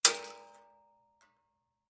shotgun_metal_1.ogg